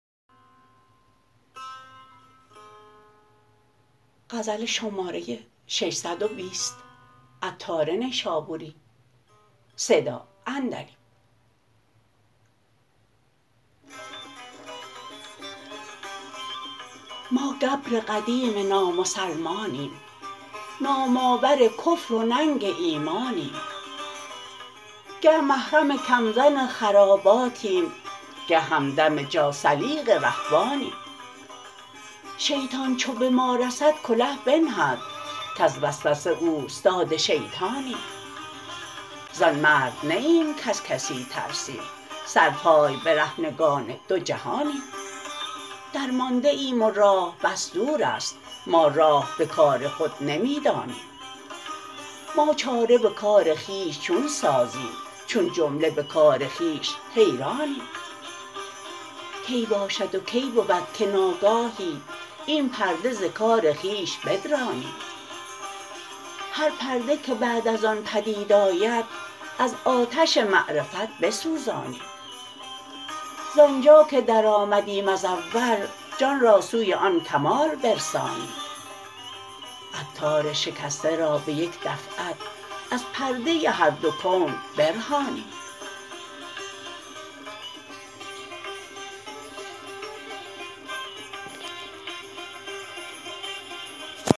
گنجور » نمایش خوانش